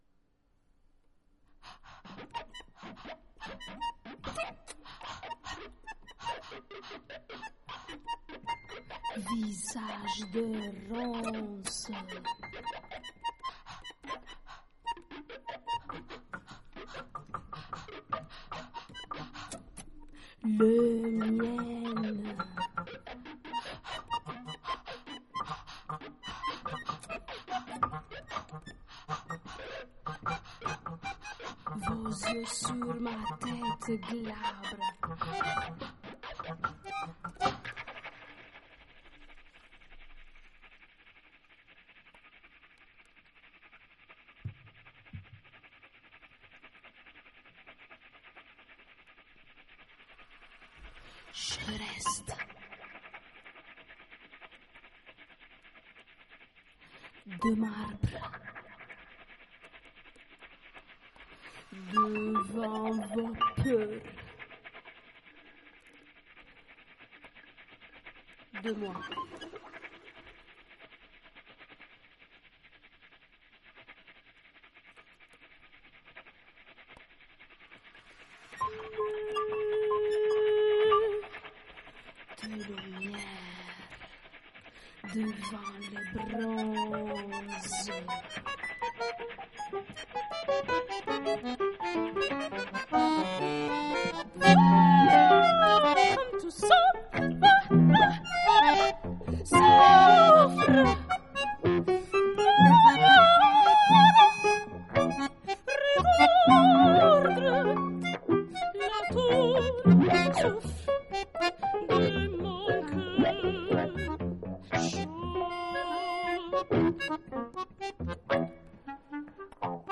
Accordéon
Piano
Guitare
Clarinette